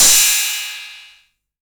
808CY_4_TapeSat.wav